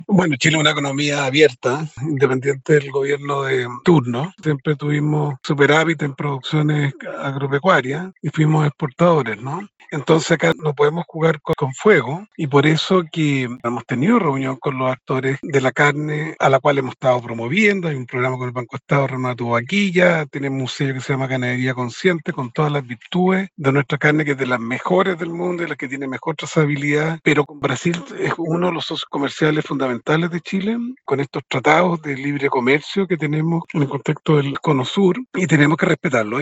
En conversación con Radio Bío Bío, el ministro afirmó que Brasil es socio comercial clave, con intercambio de vino, carne y pollo, y que los envíos se reactivaron tras una pausa sanitaria.